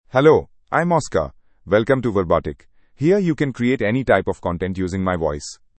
MaleEnglish (India)
Oscar is a male AI voice for English (India).
Voice sample
Listen to Oscar's male English voice.
Oscar delivers clear pronunciation with authentic India English intonation, making your content sound professionally produced.